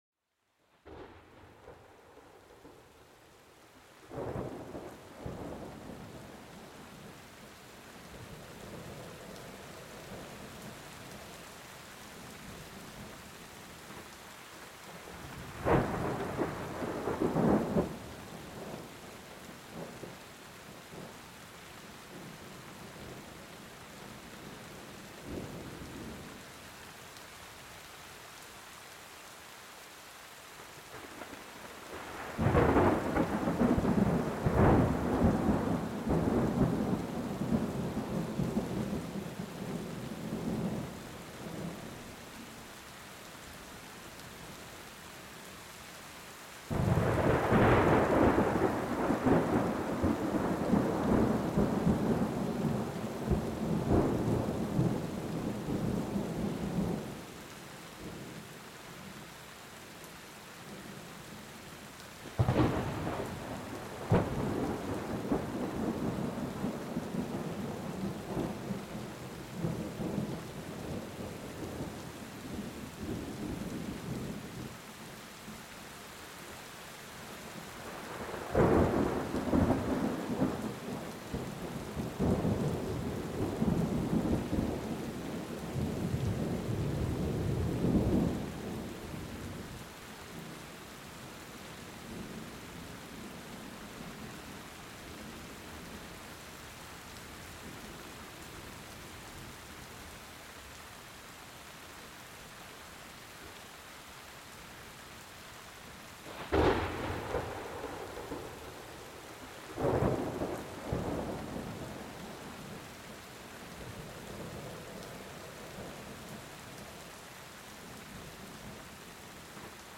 Orage et Pluie : L'Évasion Parfaite pour un Sommeil Profond
Laissez-vous emporter par les grondements apaisants de l'orage mêlés aux gouttes de pluie qui caressent la terre. Chaque éclair et chaque murmure du vent invitent votre esprit à se détendre et à s'échapper du stress quotidien.